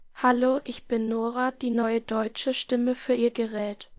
Spraaksynthese met de stem van een kind
Nora 13j (Duits):